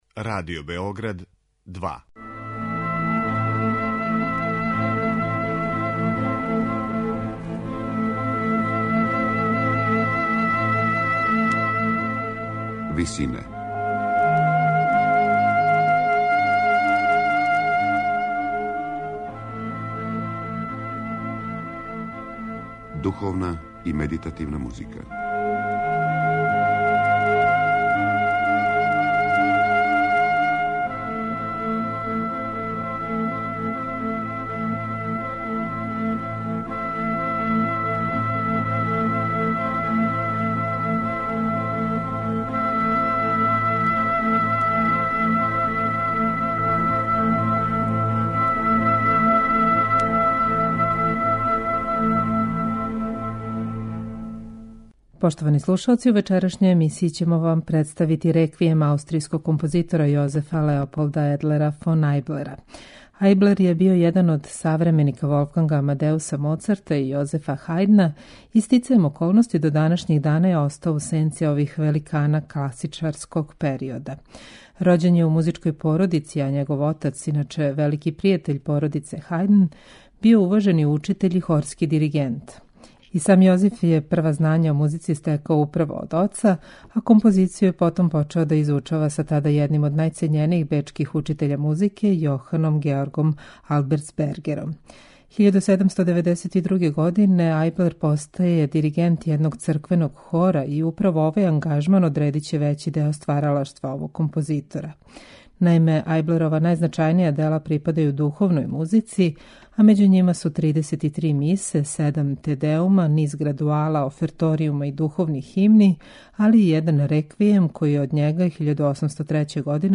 сопран
алт
тенор